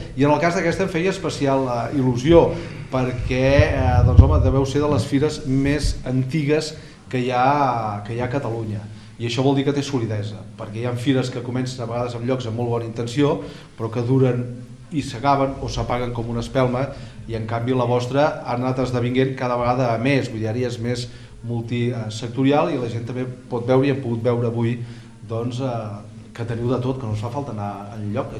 El diputat de Junts per Catalunya, Jordi Turull, ha donat el tret de sortida a la 453a edició de la Fira de Sant Vicenç de l’Espluga tallant la cinta inaugural acompanyat d’autoritats locals i territorials.